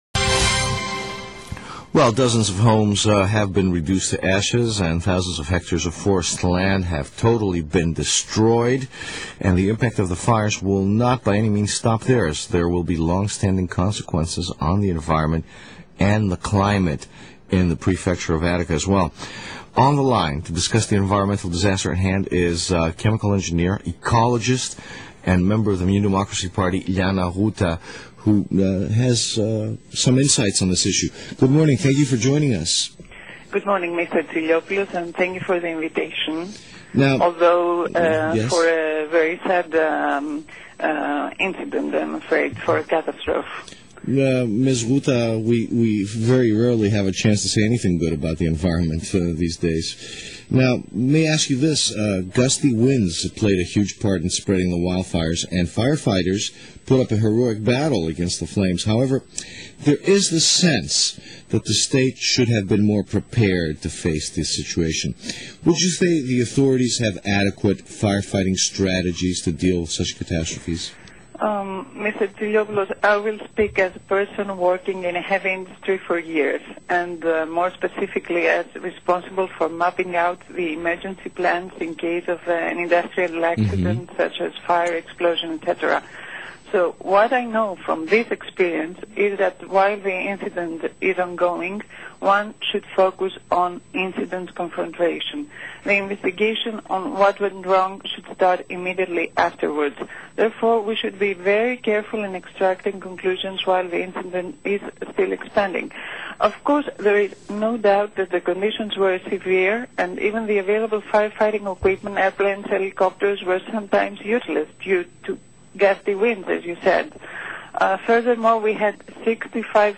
ΑΕΤΟΣ ΧΑΛΚΙΔΙΚΗΣ: Μετά τις καταστροφικές πυρκαγιές.... / Μια συνέντευξη